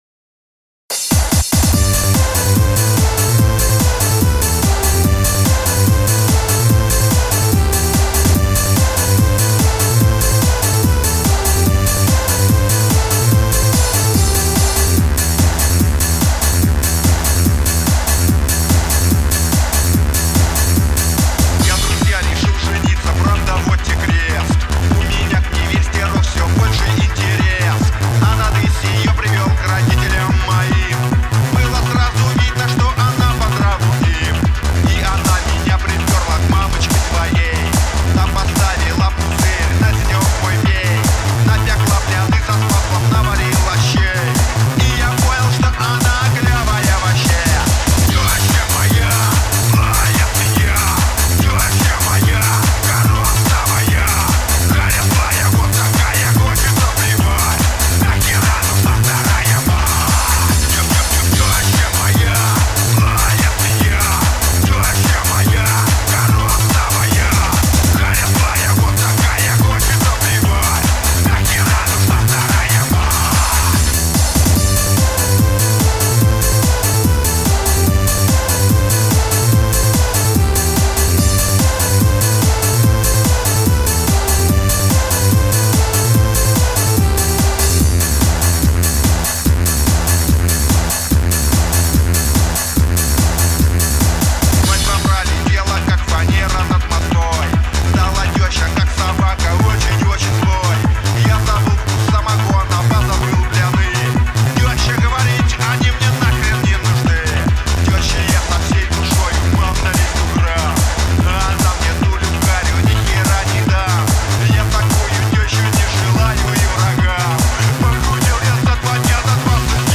Screem mix